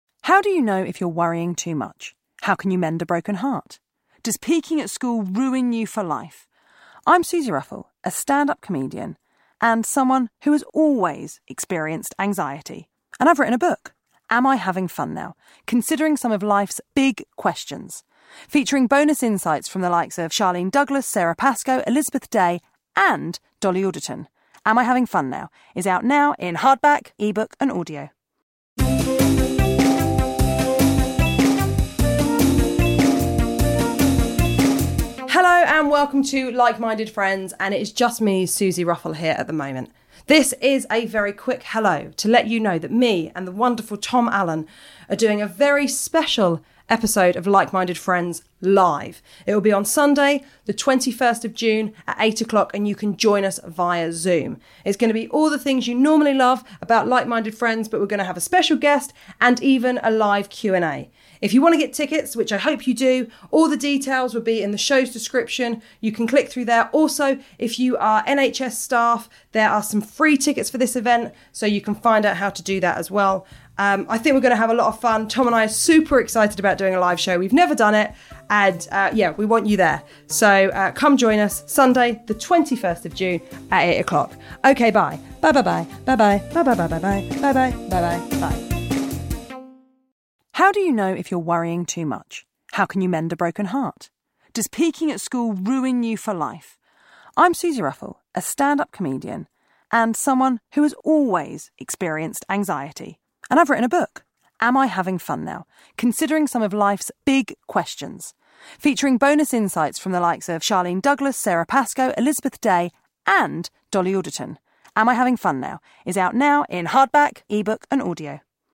A podcast where two homo comedians talk about life, love and culture... sometimes.